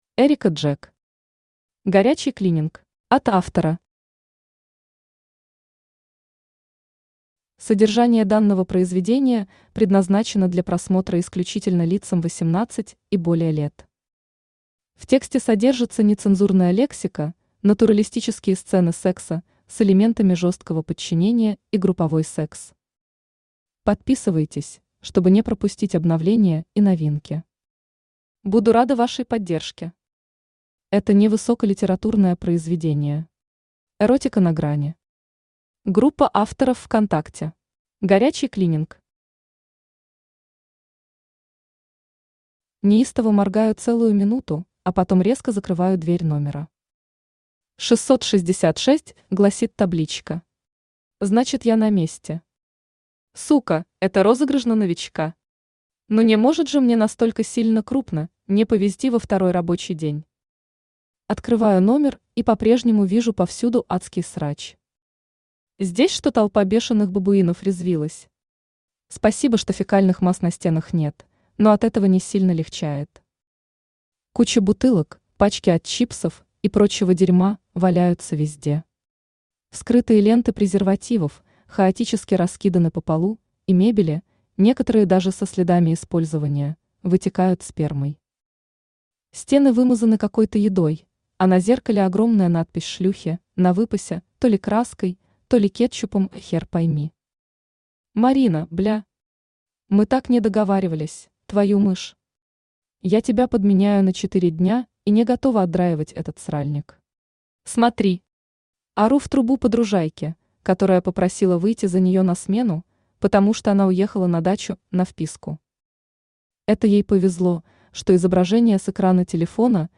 Аудиокнига Горячий клининг | Библиотека аудиокниг
Aудиокнига Горячий клининг Автор Эрика Джек Читает аудиокнигу Авточтец ЛитРес.